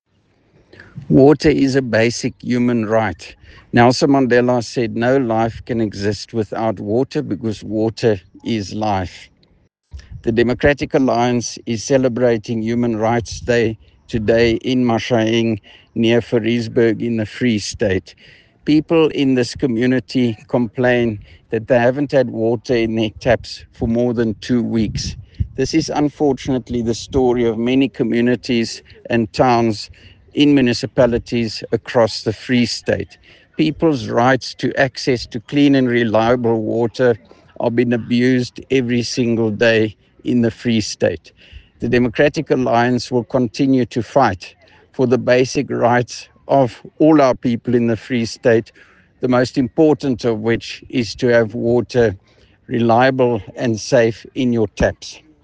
Issued by Roy Jankielsohn – DA Free State Leader
Afrikaans soundbites by Roy Jankielsohn MPL and